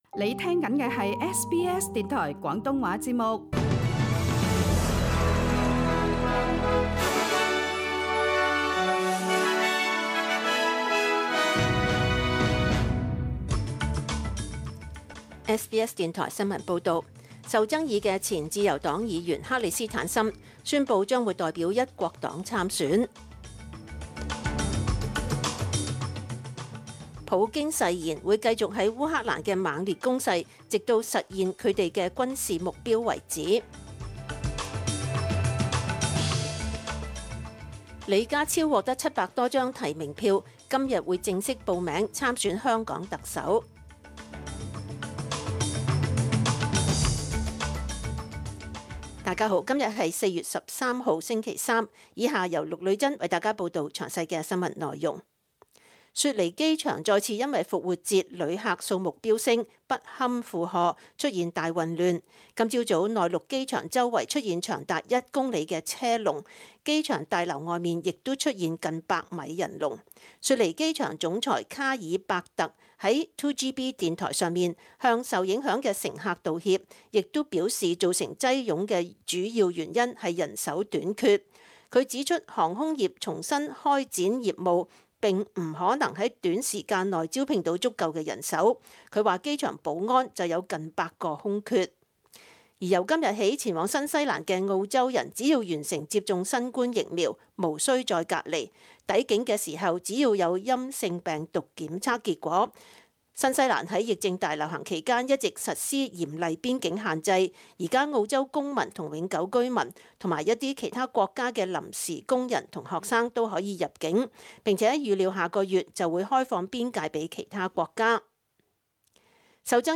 SBS 廣東話節目中文新聞 Source: SBS Cantonese